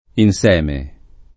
inseme [ in S$ me ]